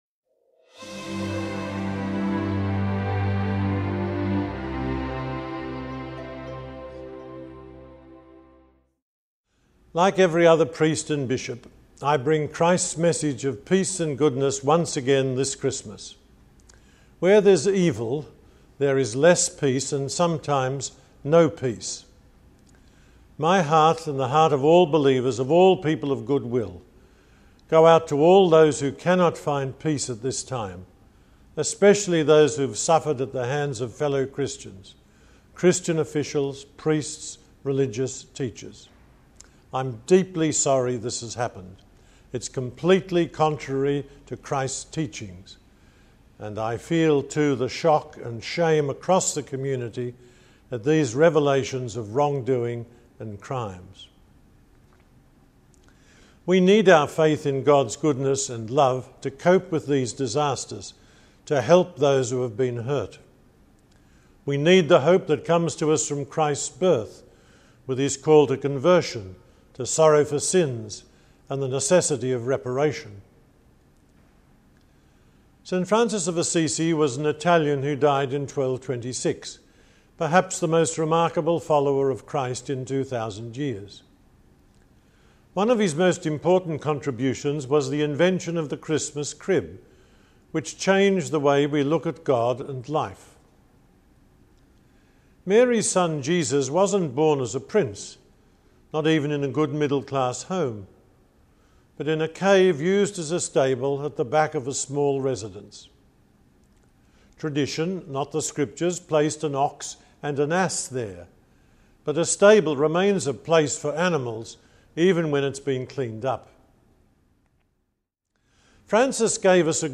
Christmas Message from Cardinal Pell
(Vatican Radio) Vatican Radio has asked Church leaders and heads of Christian charitable organisations to help us and our listeners get into the spirit of the Christmas season by contributing a message in which they share their best wishes and reflect on how the mystery of the Nativity informs our action throughout the year. Here is the message from the Archbishop of Sydney, Australia, Cardinal George Pell.